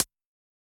Index of /musicradar/ultimate-hihat-samples/Hits/ElectroHat A
UHH_ElectroHatA_Hit-14.wav